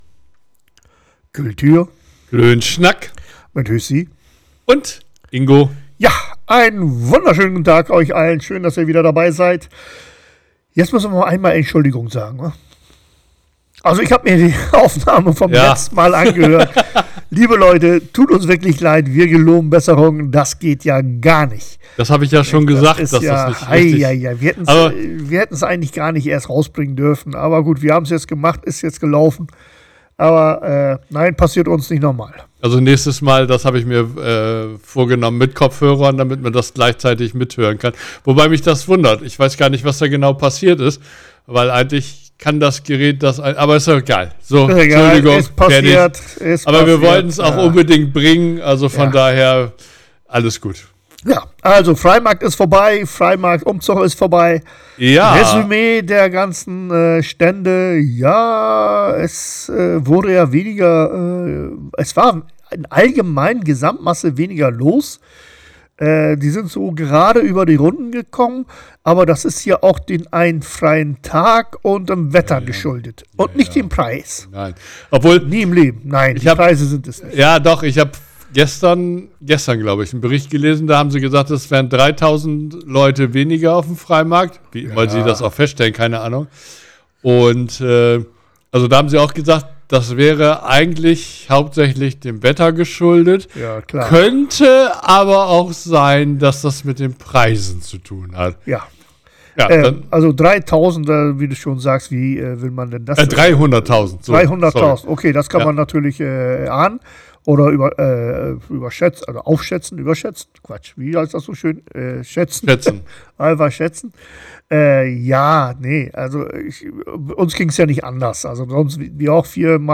Nach dem schlechten Tonqualität letzter Woche, sind wir heute wieder im Studio und alles ist wie gewohnt.